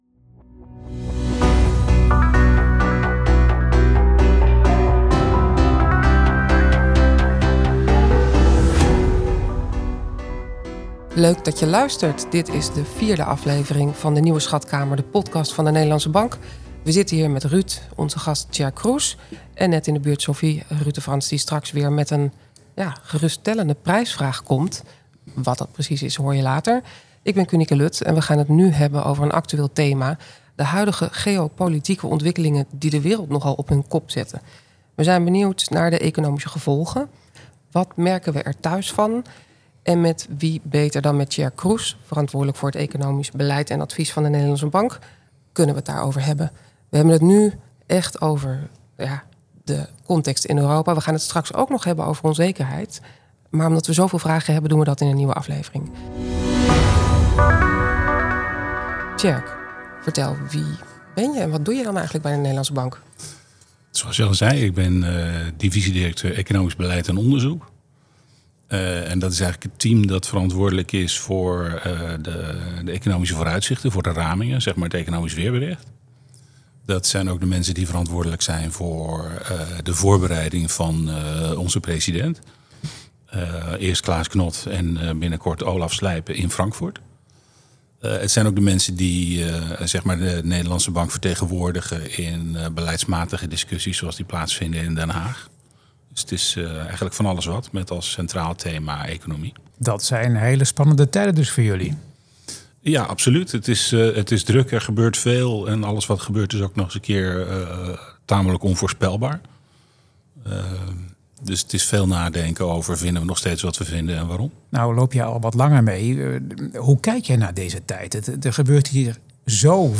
We laten onze economen aan het woord en nodigen gasten uit.